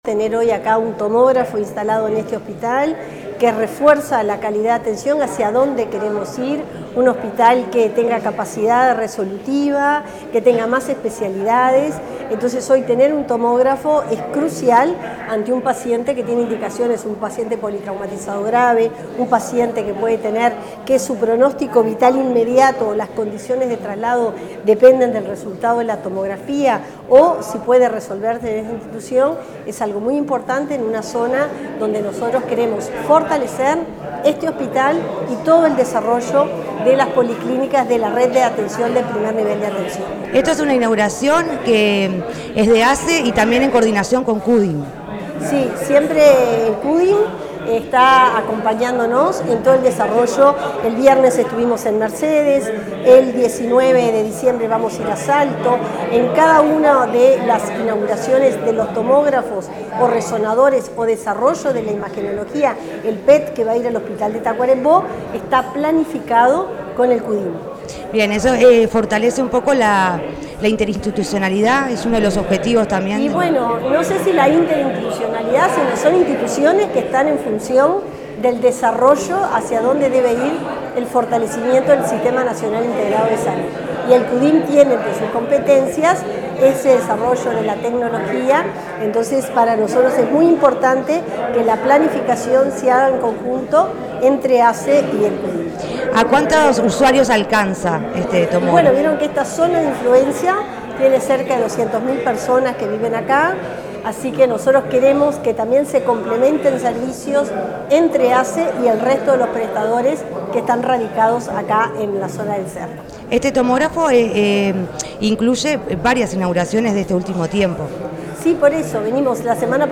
Declaraciones de la ministra de Salud Pública, Cristina Lustemberg
En el marco de la inauguración de un tomógrafo en el Hospital del Cerro, la ministra de Salud Pública, Cristina Lustemberg, destacó la trascendencia y